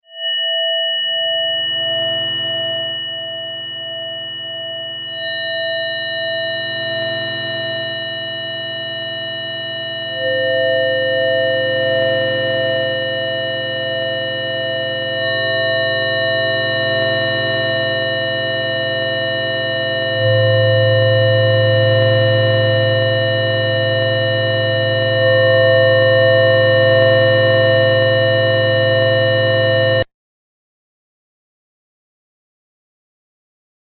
Science fiction sound fx
Metallic tones great for sci-fi films.
32kbps-Triond-tone.mp3